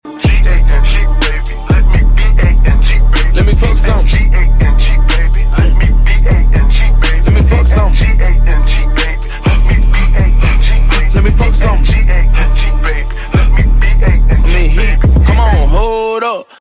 Hehe sound effects free download
Hehe Mp3 Sound Effect